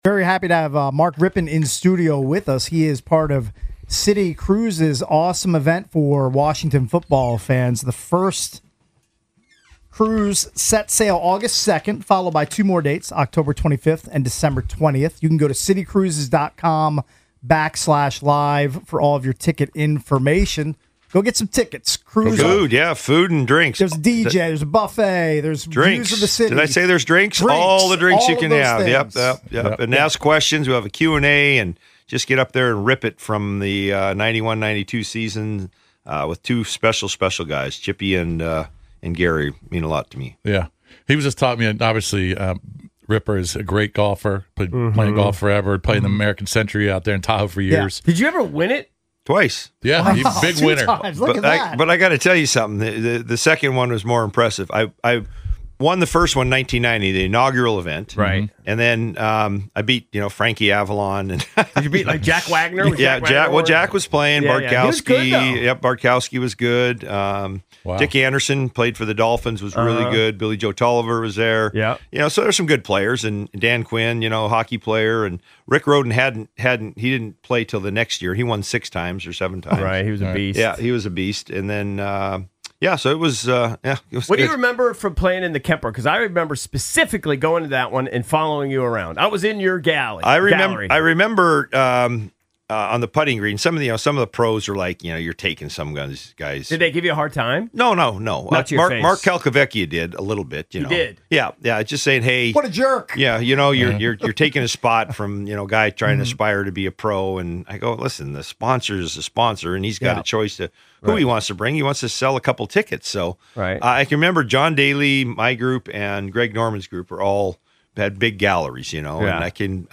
Mark Rypien Highlights His Silly Golf Game The Sports Junkies Audacy Sports, Comedy 4.6 • 1.6K Ratings 🗓 31 July 2025 ⏱ 14 minutes 🔗 Recording | iTunes | RSS 🧾 Download transcript Summary From 07/31 Hour 4: Mark Rypien joins The Sports Junkies in studio (part two).